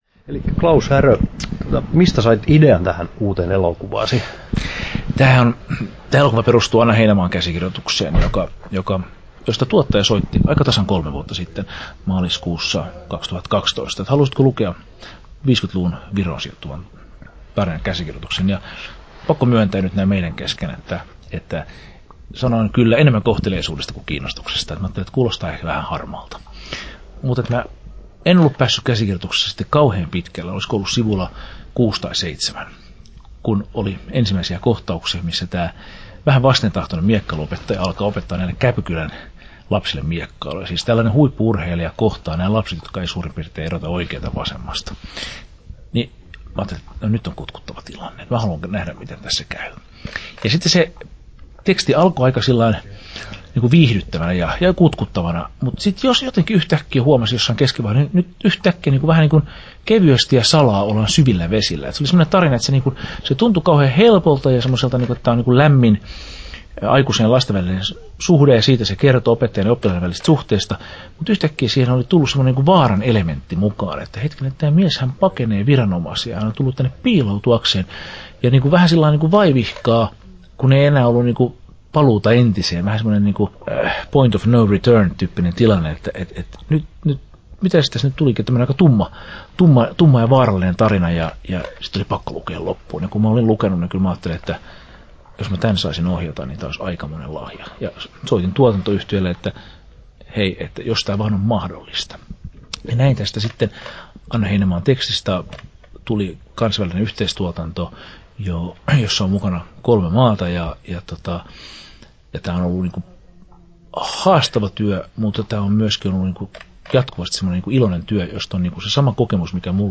Klaus Härö ja Miekkailija • Haastattelut
Tallennettu: 27.2.2015, Turku